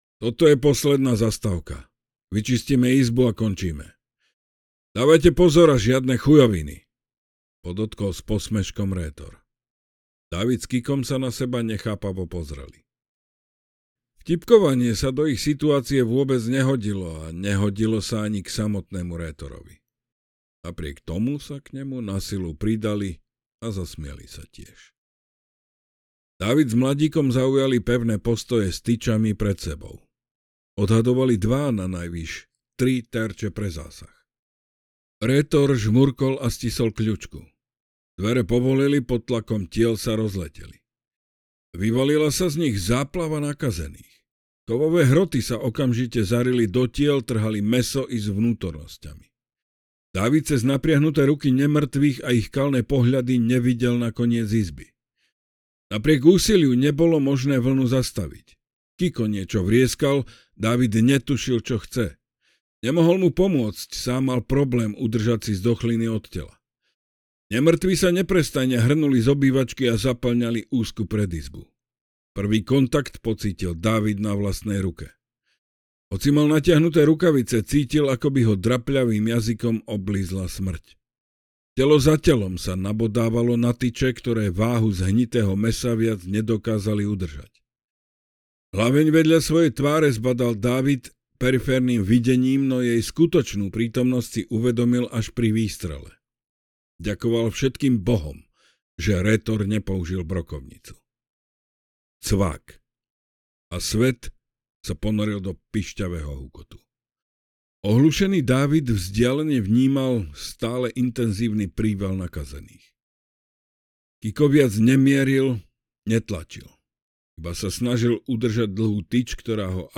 Zima mŕtvych audiokniha
Ukázka z knihy